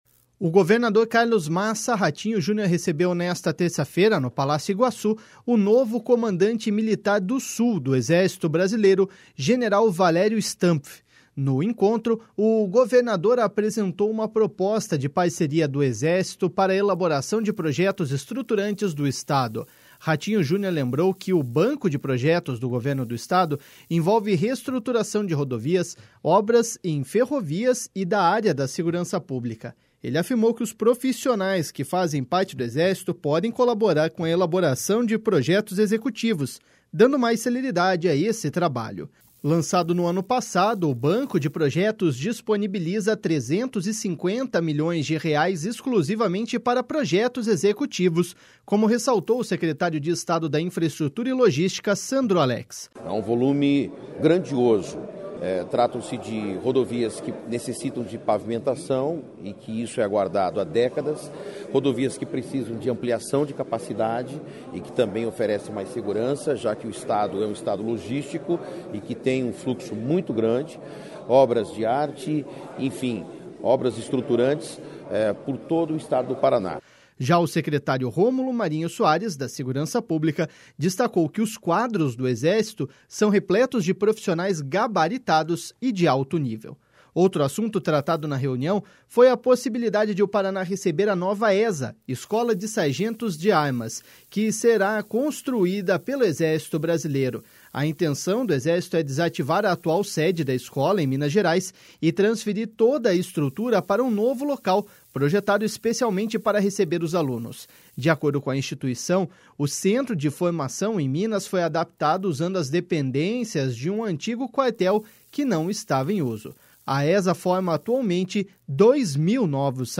Lançado no ano passado, o Banco de Projetos disponibiliza 350 milhões de reais exclusivamente para projetos executivosa, como ressaltou o secretário de Estado da Infraestrutura e Logística, Sandro Alex.// SONORA SANDRO ALEX.//